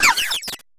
Cri de Lilliterelle dans Pokémon HOME.